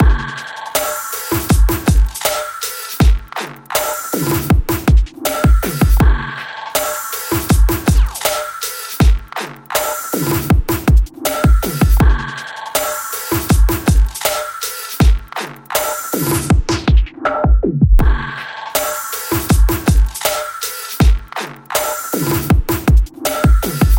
打击乐电子滴水鼓循环
标签： 160 bpm Electro Loops Drum Loops 1.01 MB wav Key : Unknown
声道立体声